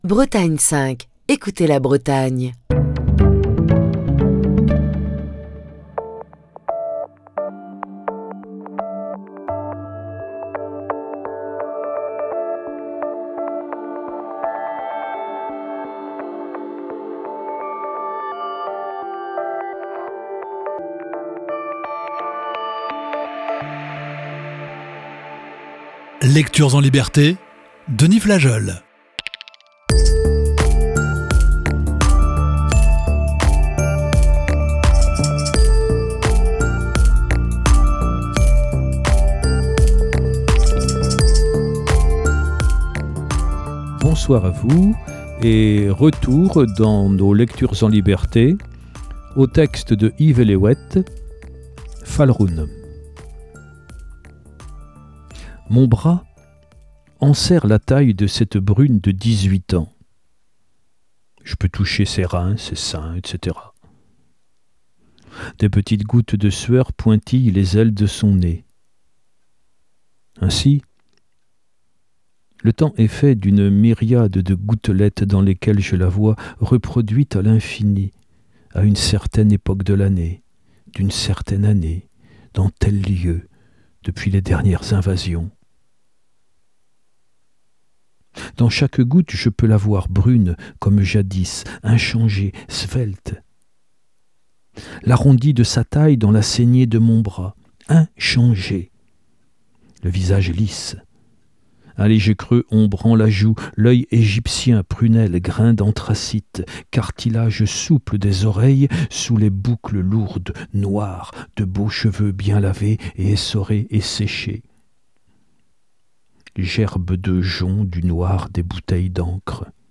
la lecture de "Falc'hun", d'Yves Elléouët.